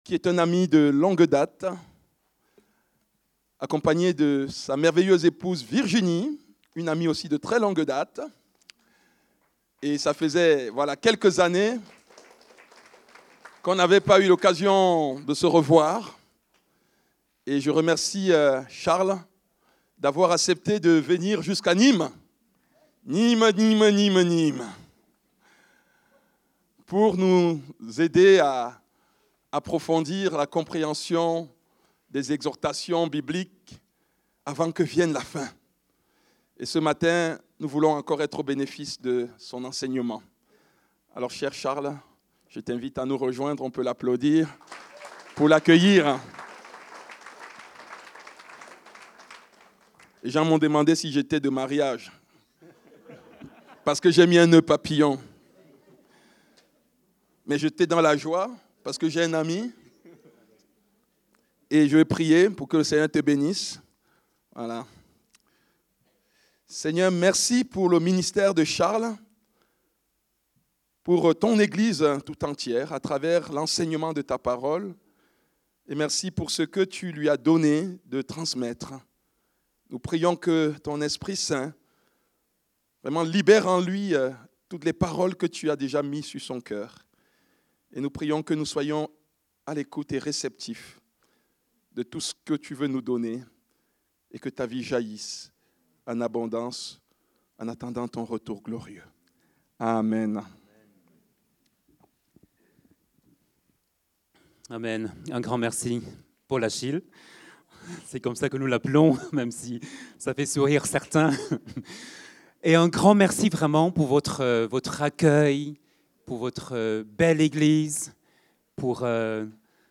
Culte du dimanche 12 octobre 2025